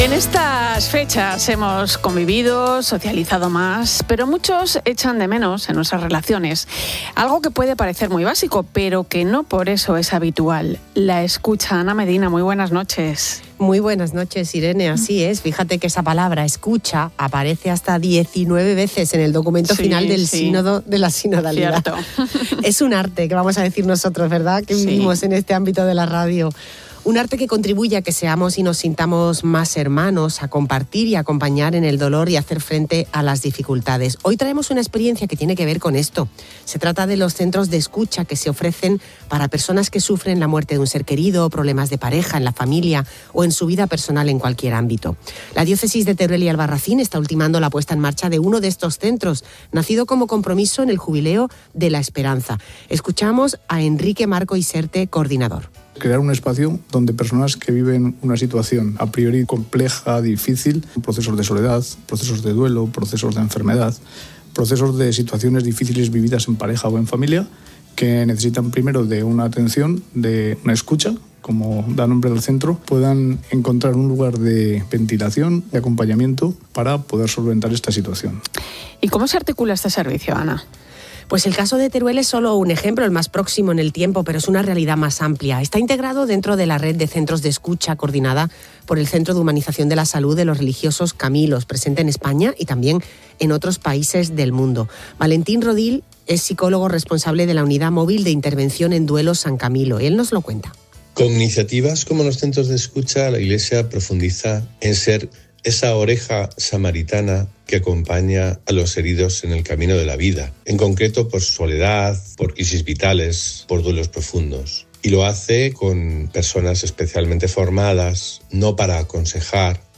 El reportaje también puso de relieve la formación específica que están recibiendo los voluntarios, con el fin de ofrecer un servicio responsable, seguro y profesional.